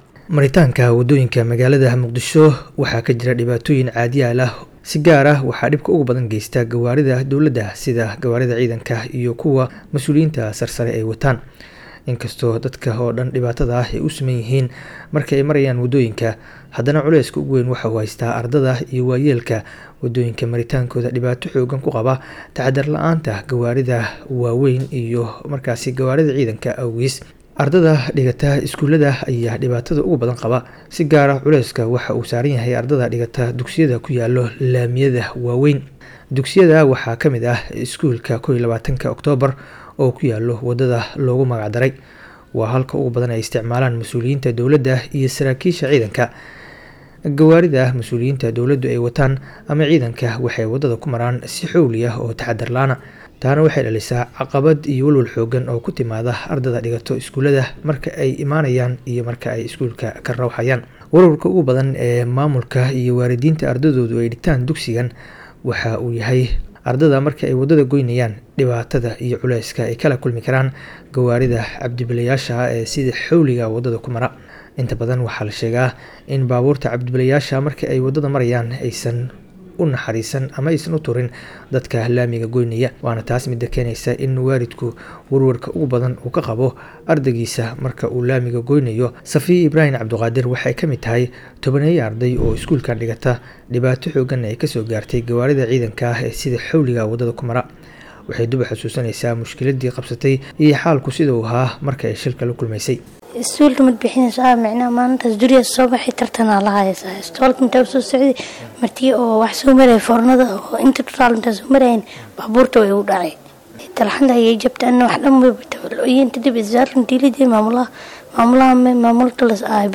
Ardey dhibbane u ah Gaadiidka Dowladda, warbixin maqal